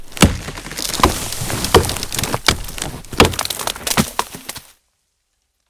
chop.ogg